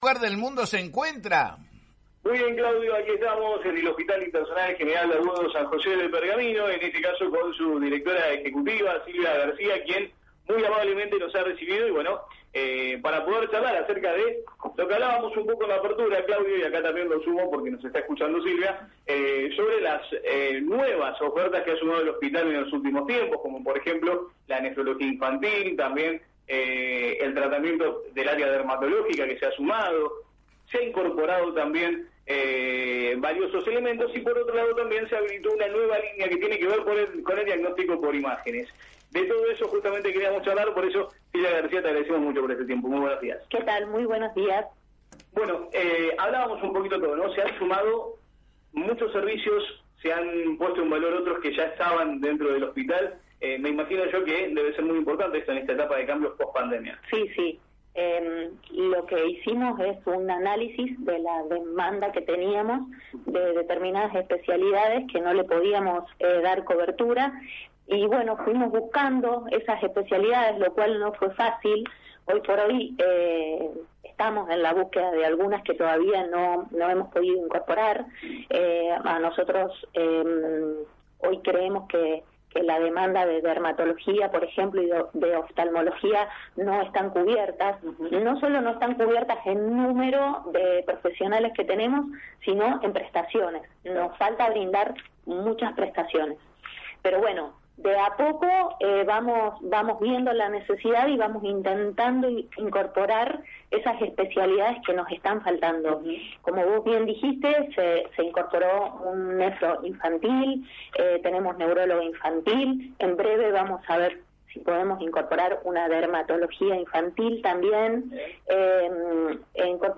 diálogo con el móvil de «La Mañana de la Radio» y comentó sobre la actualidad del Hospital.